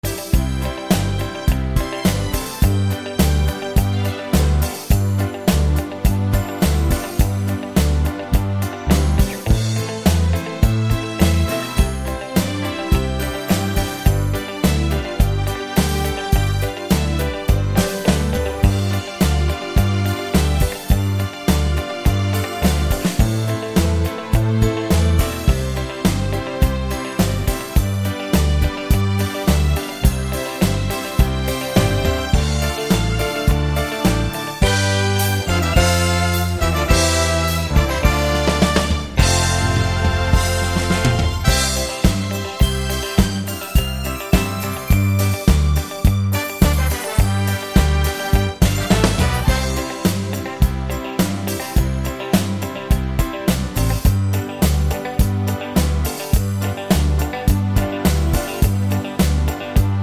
no Backing Vocals Crooners 3:15 Buy £1.50